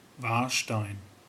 Warstein ([ˈvaːɐ̯ˌʃtaɪn]